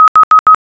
■アラーム
フリーのＳＥ作成ソフトで作っています。